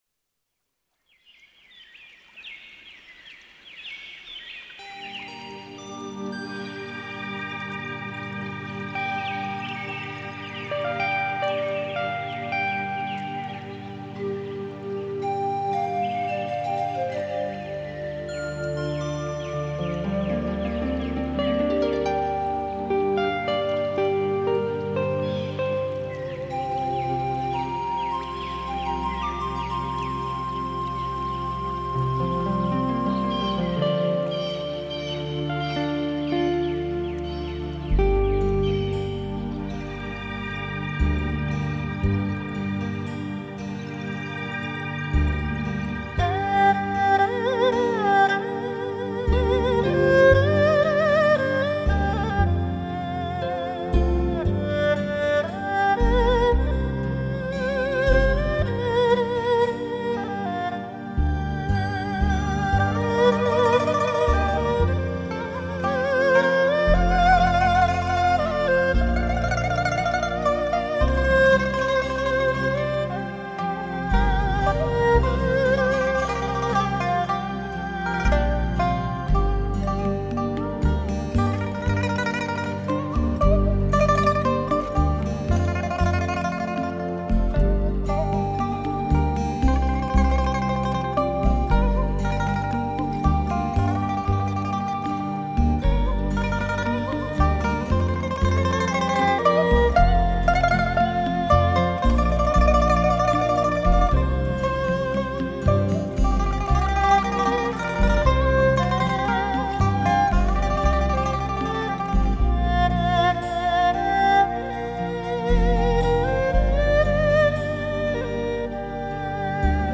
二胡、古筝、琵琶、笙、笛等乐器，一一化作采茶女的悠扬歌声。
全碟有幽雅飘逸的的旋律,有平易而有浓浓的深情,还有辽阔而宽广的乐音, 让我们感受到了一种人和自然融合的景象。